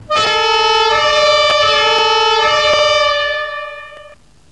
Sirenensignale/Martinshorn
Horn3.mp3